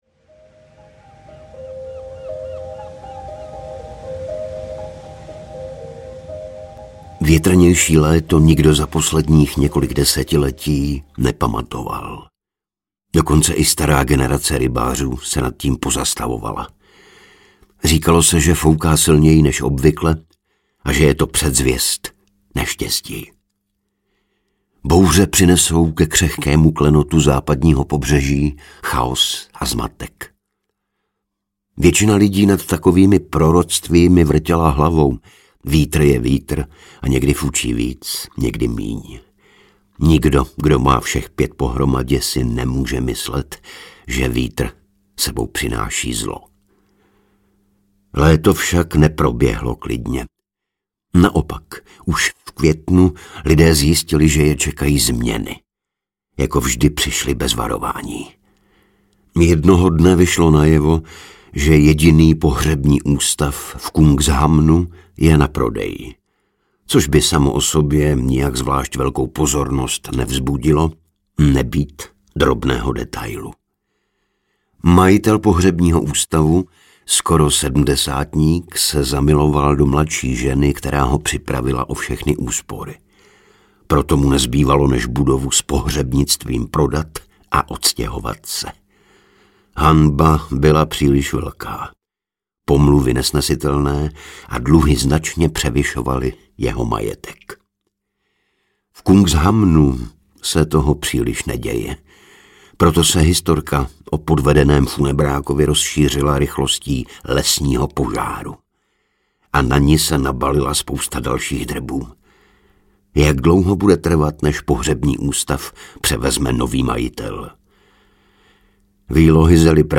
Bouře audiokniha
Ukázka z knihy
• InterpretIgor Bareš, Lucie Juřičková